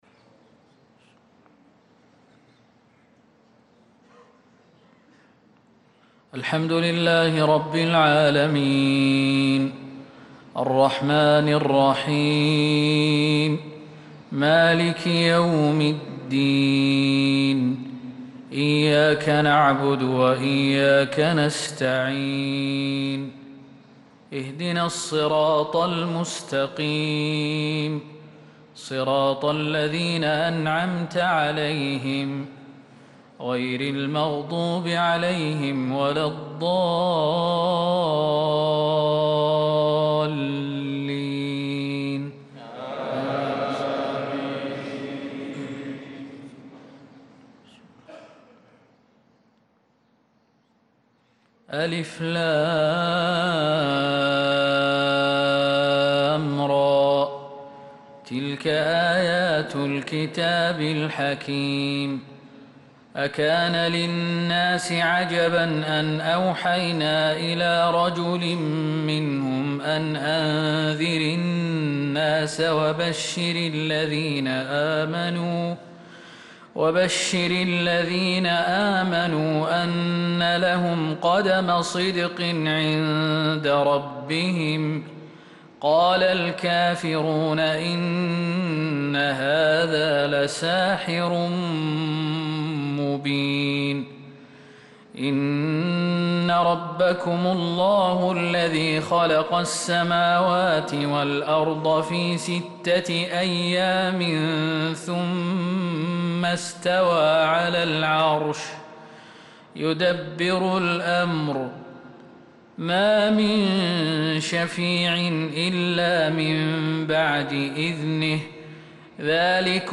صلاة الفجر للقارئ خالد المهنا 13 ذو الحجة 1445 هـ
تِلَاوَات الْحَرَمَيْن .